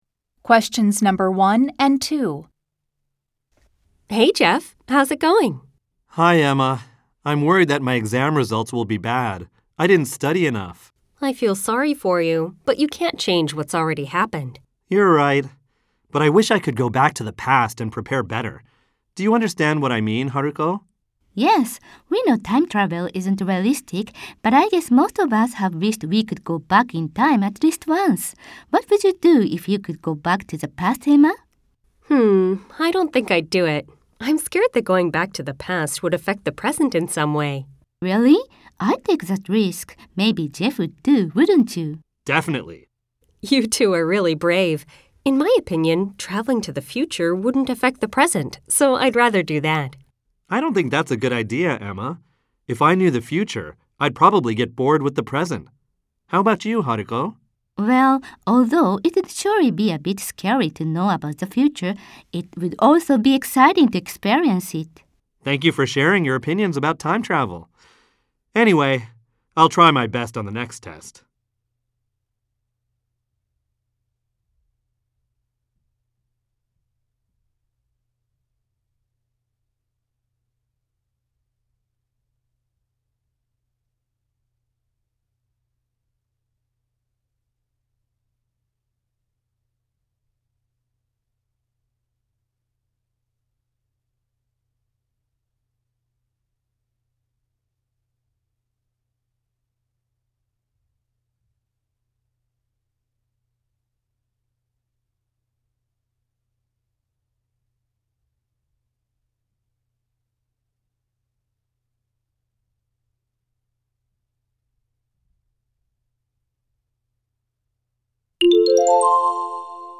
分野別は高速音声も収録。
設問によってイギリス英語話者や非ネイティブ話者も登場。
Lesson 9　高速 A-74